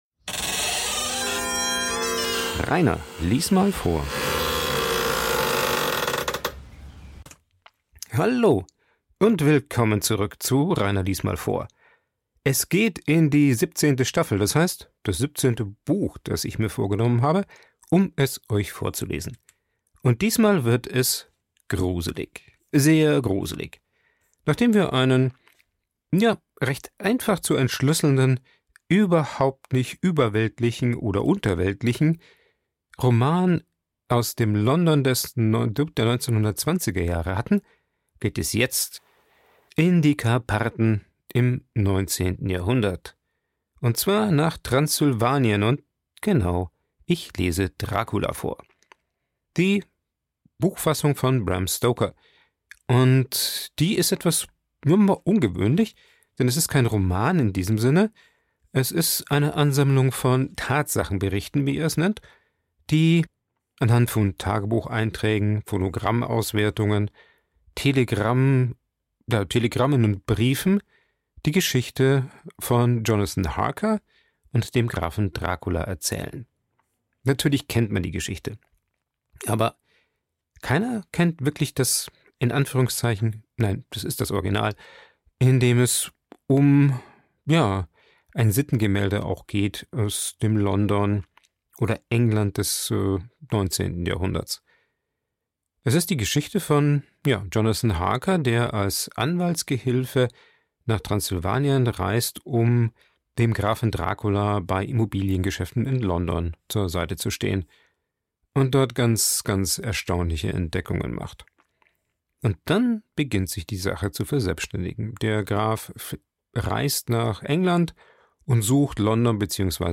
Ein Vorlese Podcast